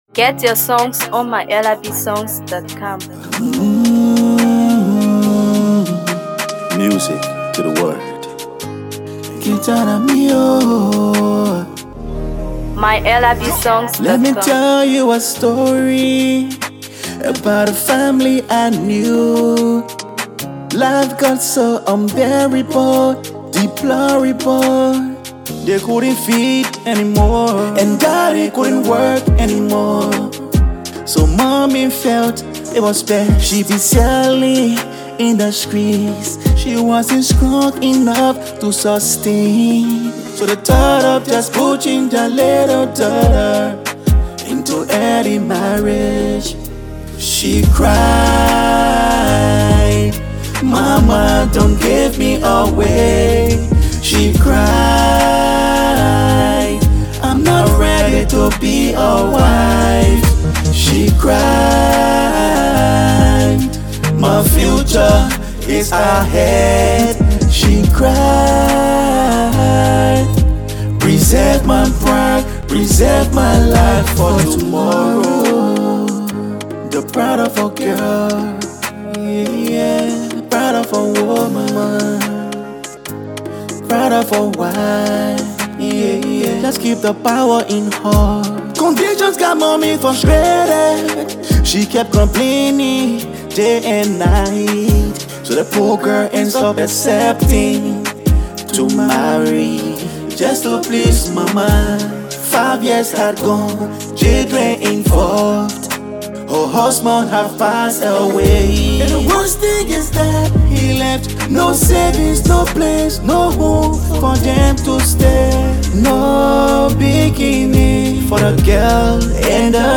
sensational rapper
powerful and emotionally stirring song